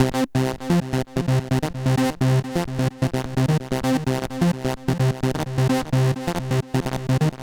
synth01.wav